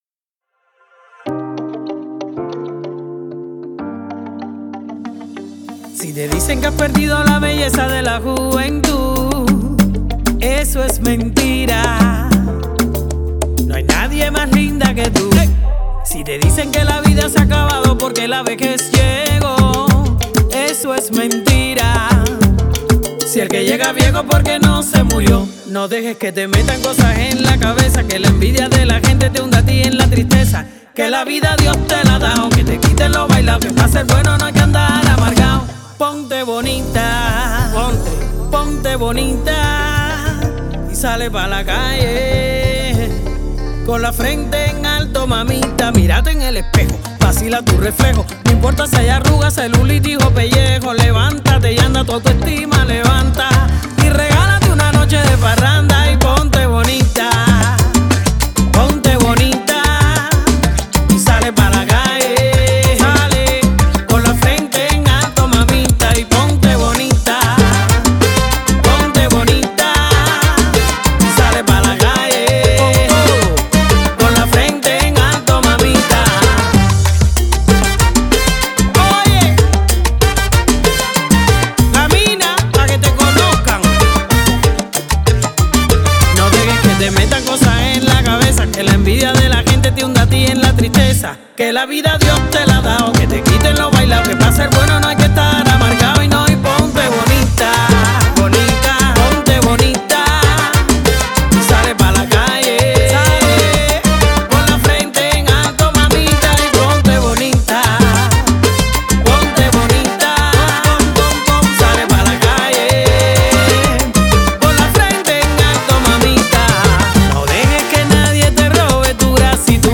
cantante cubana galardonada con Grammy y Latin Grammy